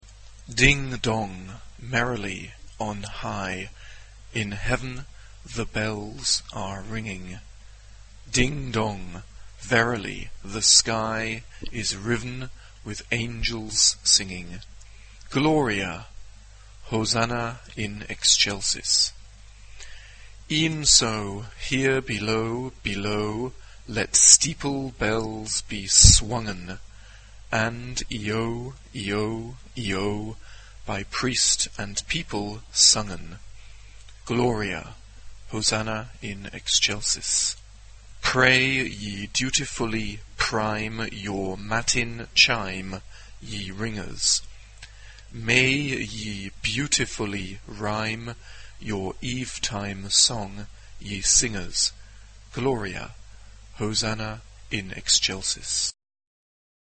Instruments: Keyboard (1)
Tonality: B flat major